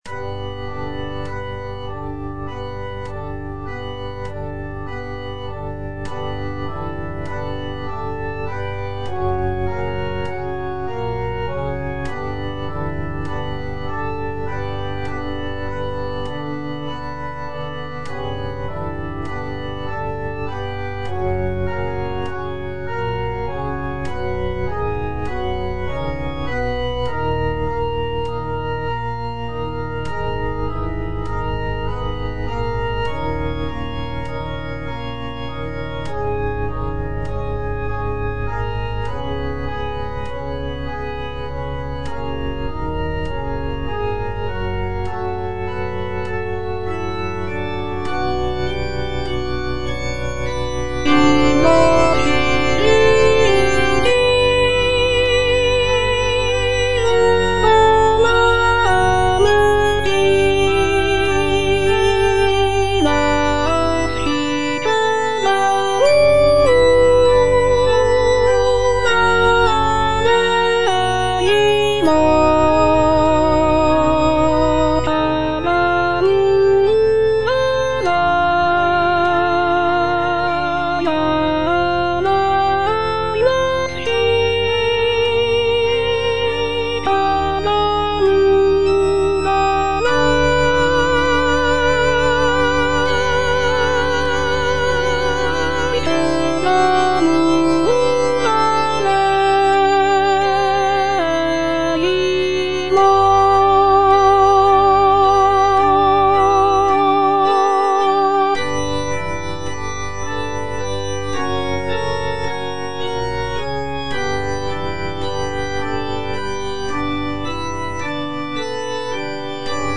Alto (Voice with metronome) Ads stop